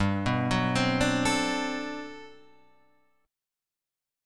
G Major 11th